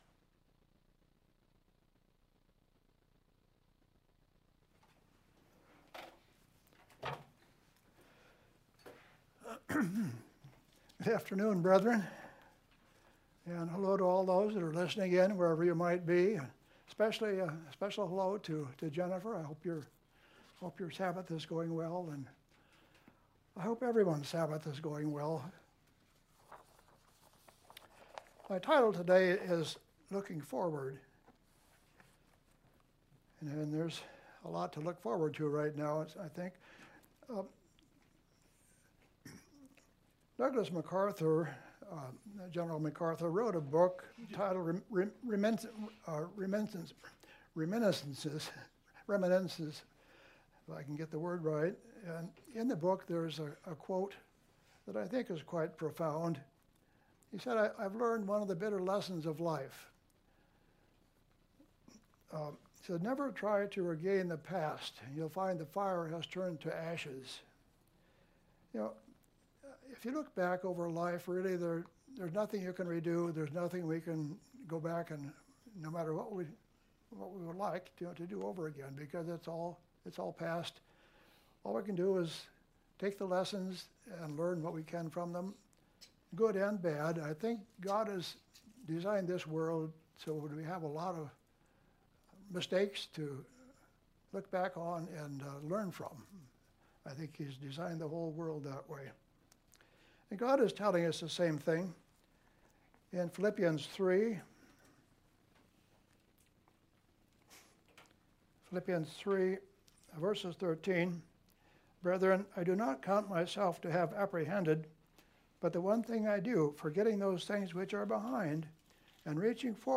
New Sermon | PacificCoG
From Location: "Kennewick, WA"